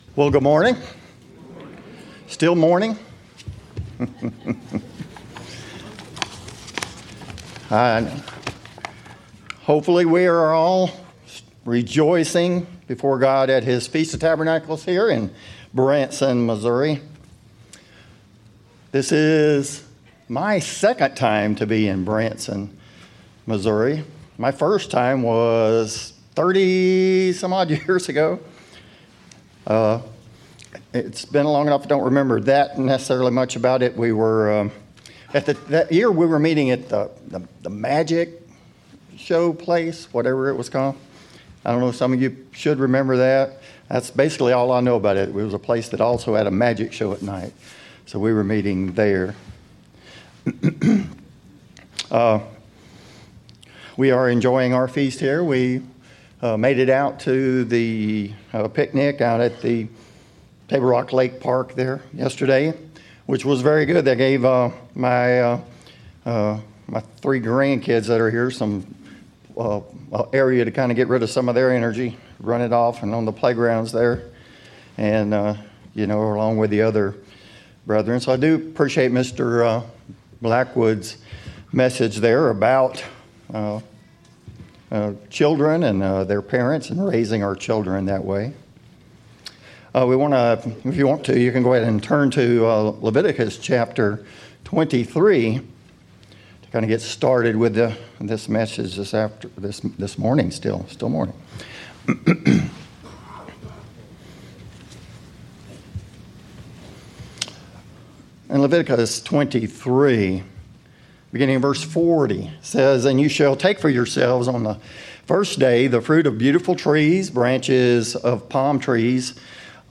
Sermons
Given in Branson, Missouri